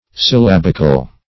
Search Result for " syllabical" : The Collaborative International Dictionary of English v.0.48: Syllabic \Syl*lab"ic\, Syllabical \Syl*lab"ic*al\, a. [Gr.